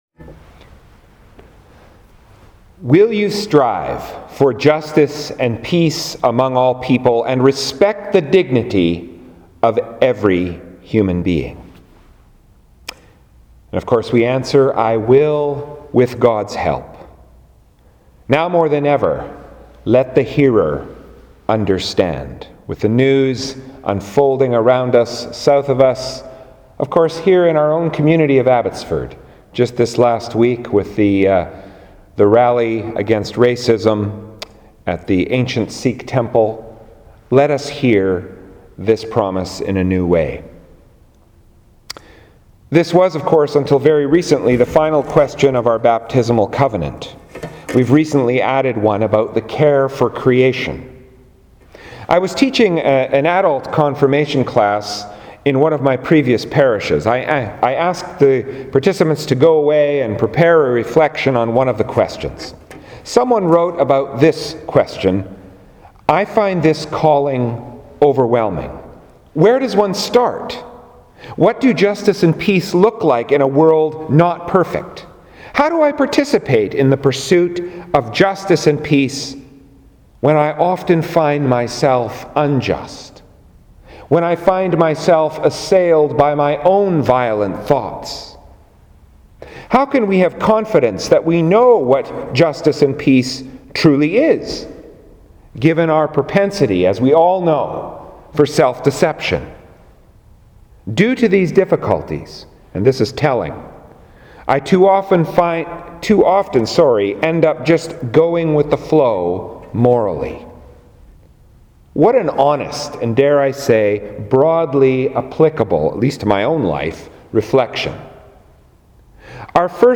Sermons | Parish of St. Matthew Anglican Church